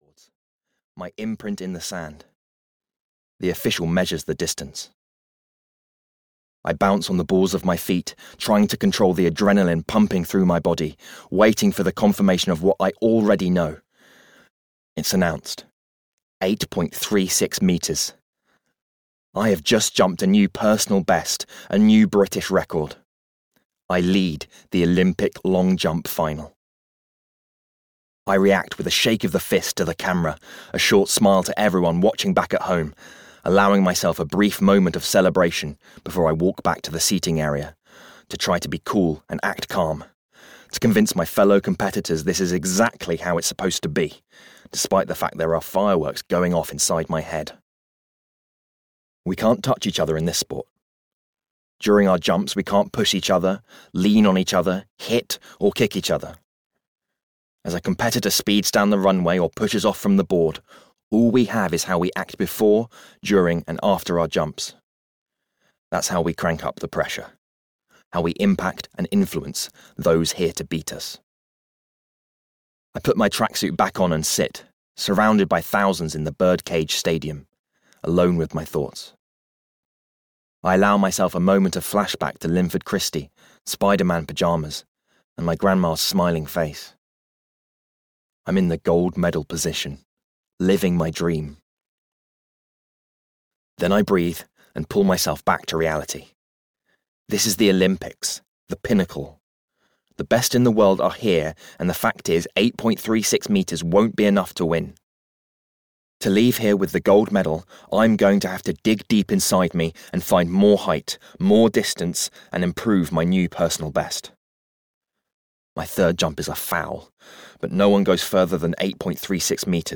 Running and Jumping (EN) audiokniha
Ukázka z knihy